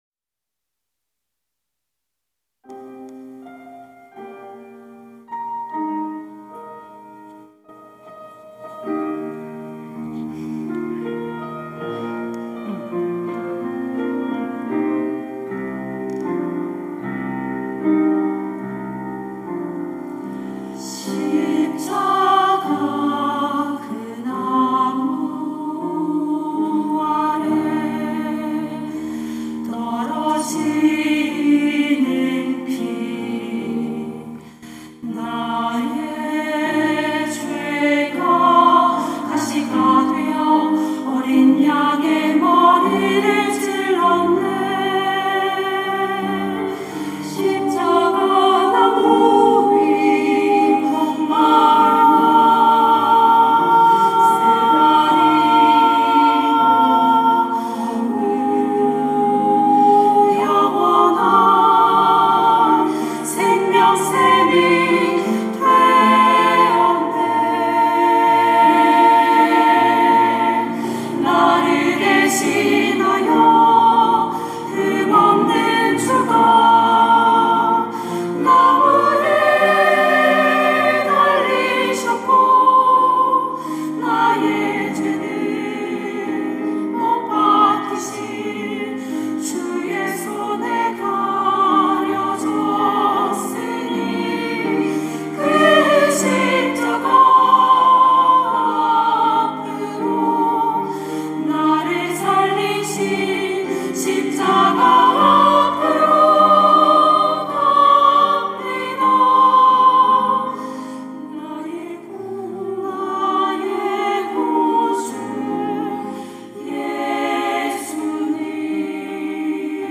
천안중앙교회
찬양대 글로리아